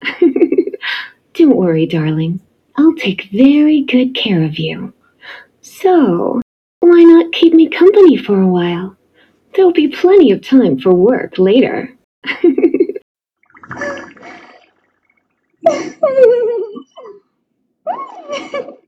Sus Anime Genshin Voicelines: Instant Play Sound Effect Button